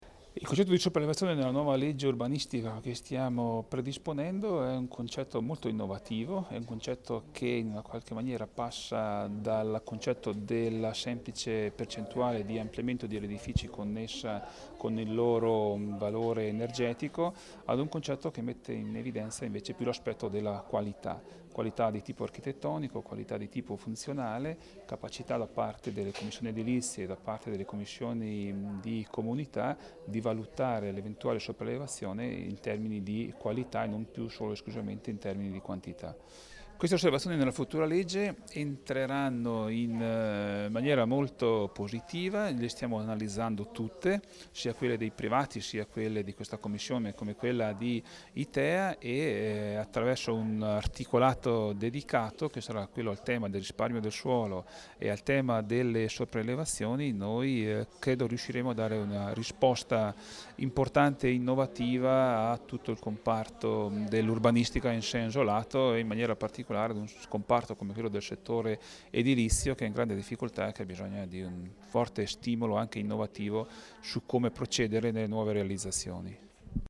int_ass._Daldoss.mp3